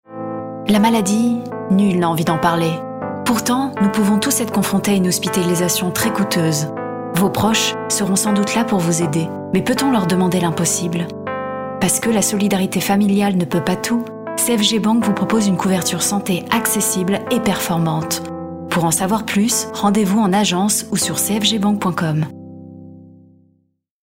Voix Off Femme
VIDEO EXPLICATIVE